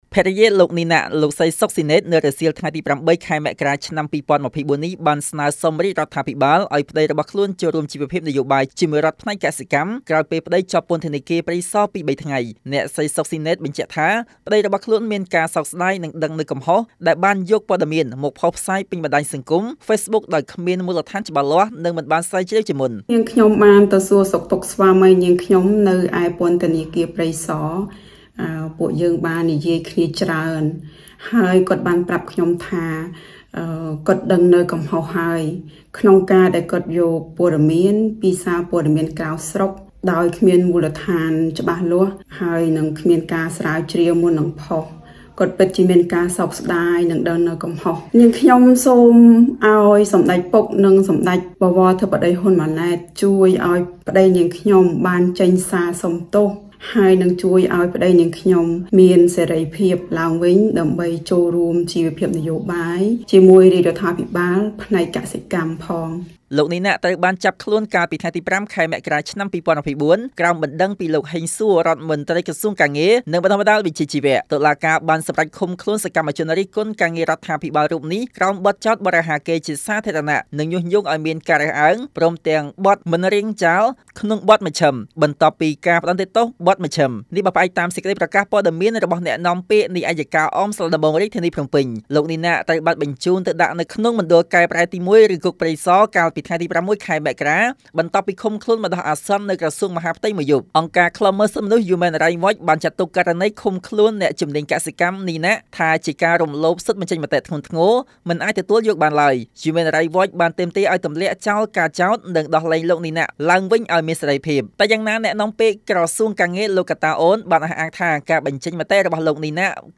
ជូននូវសេចក្ដីរាយការណ៍៖